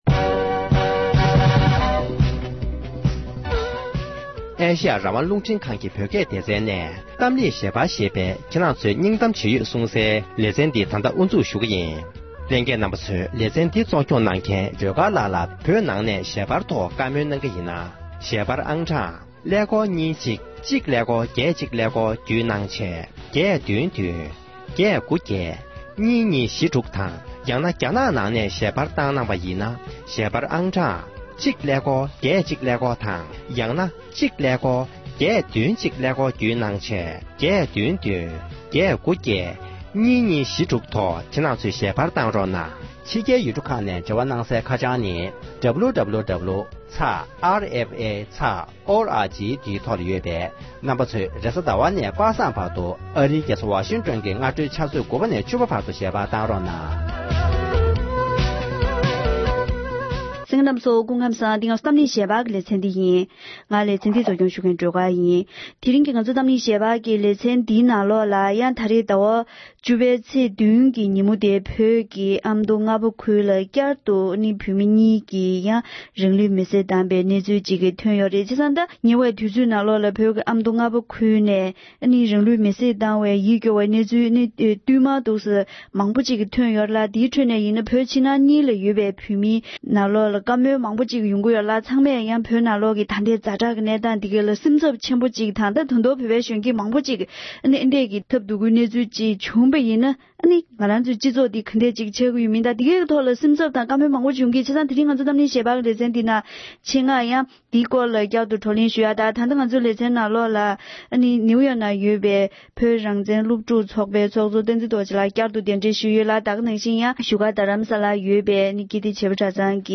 འབྲེལ་ཡོད་མི་སྣར་བཀའ་འདྲི་ཞུས་པ་ཞིག་ལ་གསན་རོགས་ཞུ༎